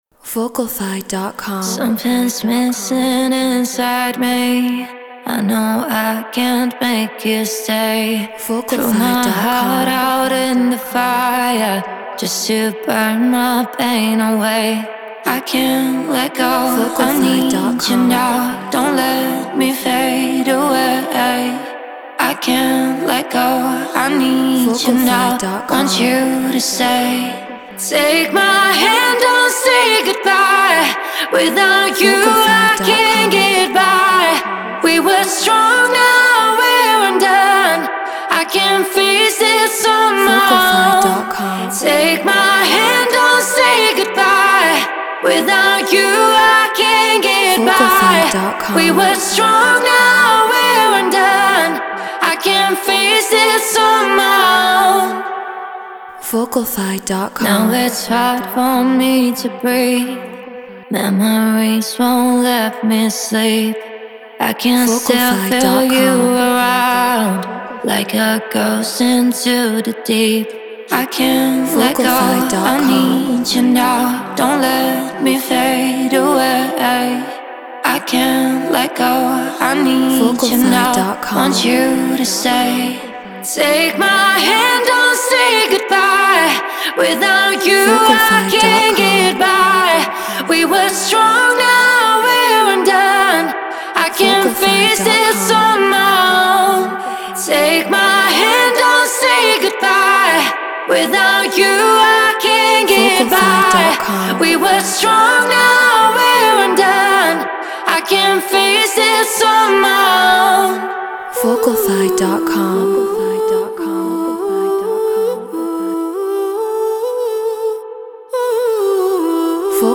Garage 138 BPM Emin
Human-Made
Brauner VMX Apogee Elements 88 Ableton Live Treated Room